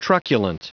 Prononciation du mot truculent en anglais (fichier audio)